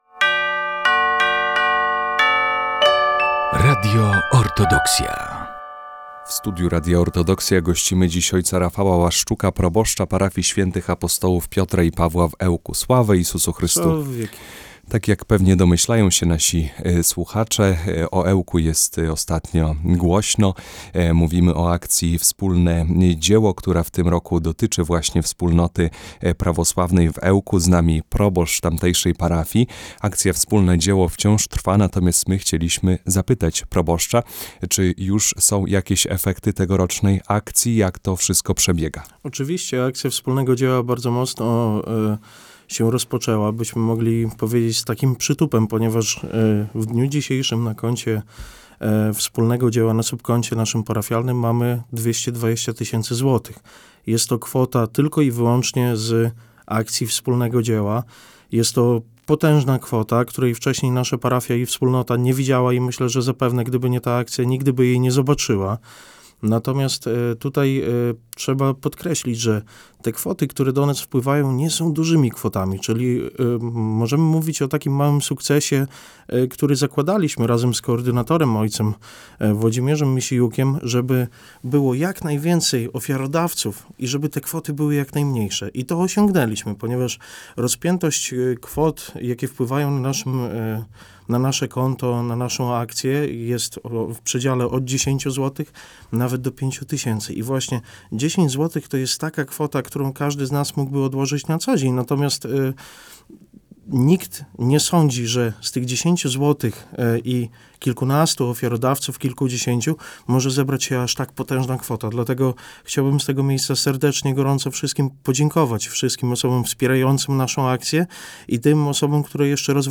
Trwa Akcja Wspólne Dzieło 2025. Rozmowa